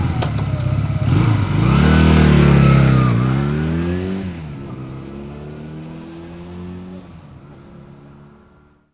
Index of /90_sSampleCDs/AKAI S6000 CD-ROM - Volume 6/Transportation/MOTORCYCLE
400-AWAY.WAV